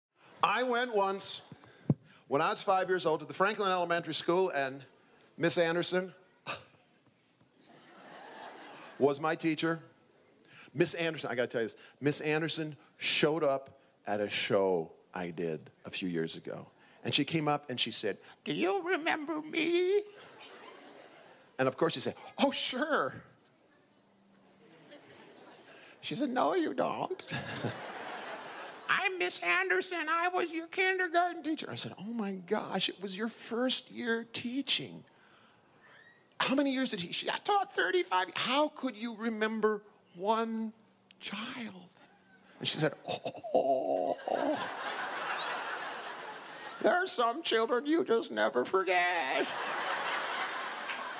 Build your story collection with this exclusive Festival double CD sampler—a compilation of select stories from the 2012 Timpanogos Storytelling Festival.